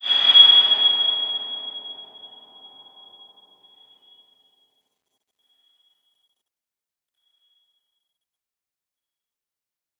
X_BasicBells-G#5-pp.wav